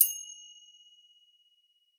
finger_cymbals_side02
bell chime cymbal ding finger-cymbals orchestral percussion sound effect free sound royalty free Sound Effects